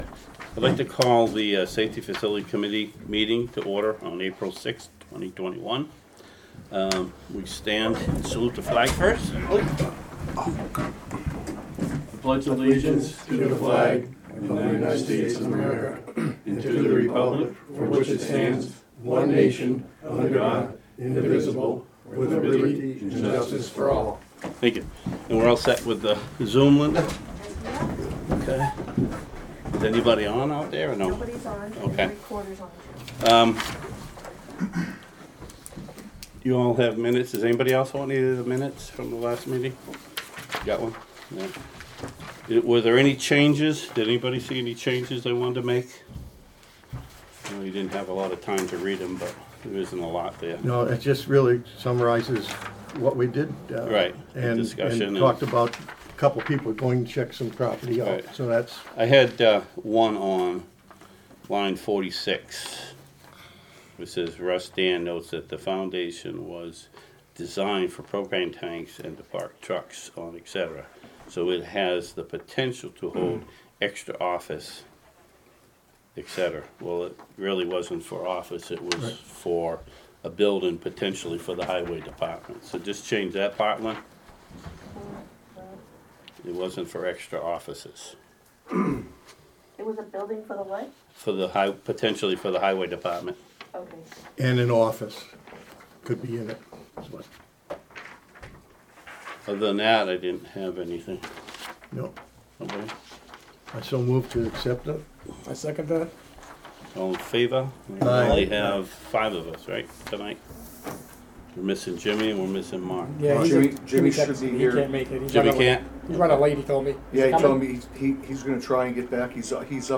Audio recordings of committee and board meetings.
Safety Facility Committee Meeting